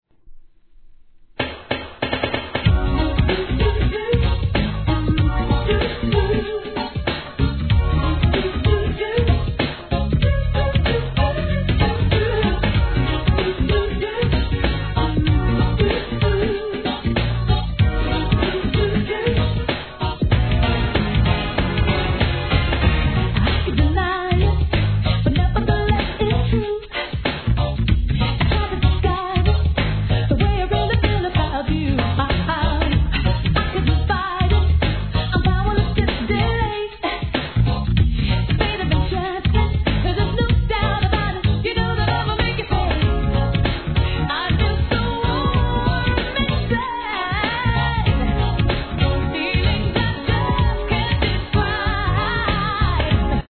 HIP HOP/R&B
高揚感溢れるダンサブルかつポップなオケに、弾けんばかりのキュートなヴォーカル! キャッチー跳ね系決定盤!!